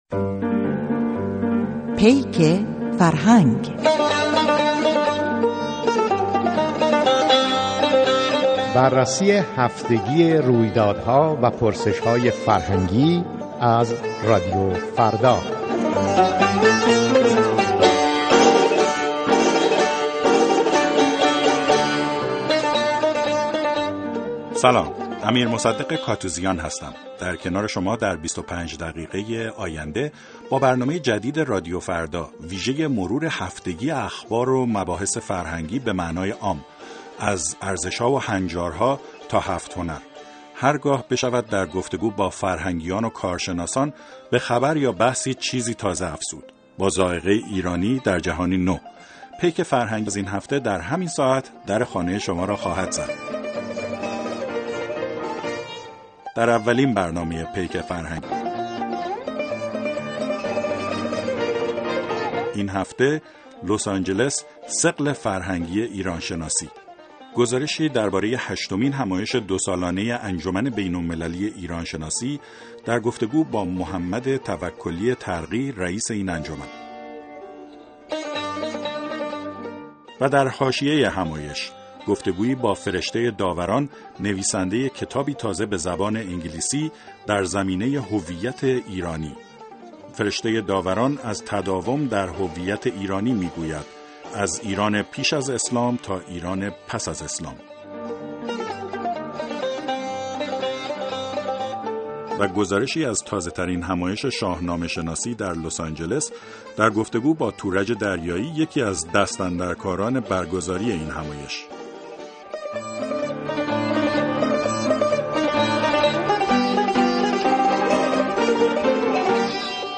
پیک‌فرهنگ (برنامه هفتگی جدید رادیو‌فردا) شامل مصاحبه